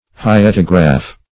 Search Result for " hyetograph" : The Collaborative International Dictionary of English v.0.48: Hyetograph \Hy"e*to*graph\, n. [Gr.